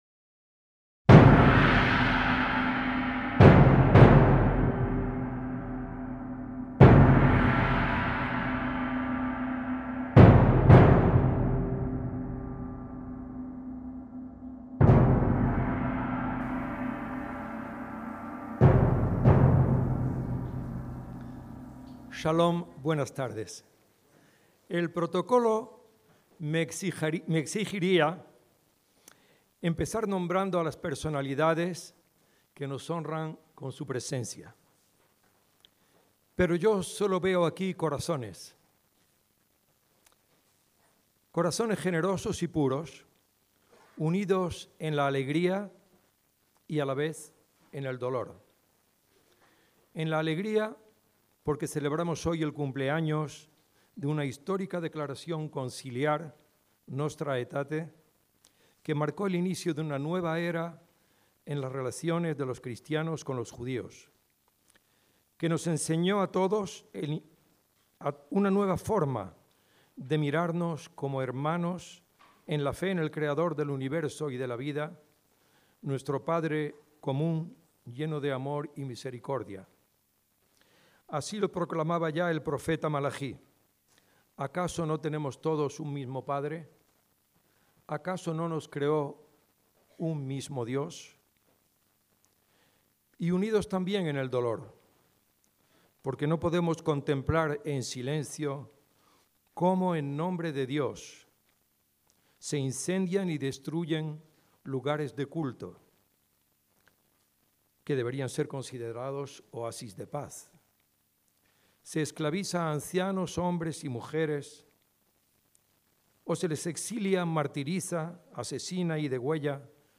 Acto de solidaridad con los cristianos en Oriente Medio y en África (CJM, Madrid, 23/5/2015)